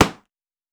Glove Catch Intense.wav